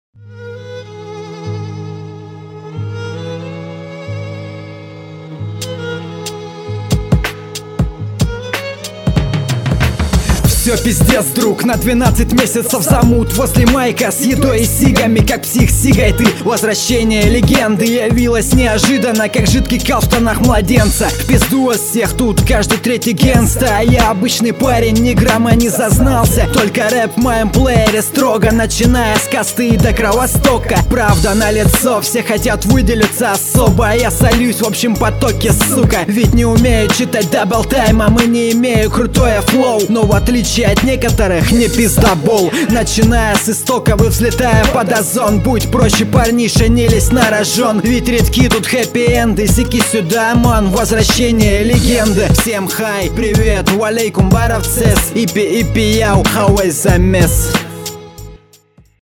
Было бы в ритм было айс, а так только текст местами совсем местами интересный!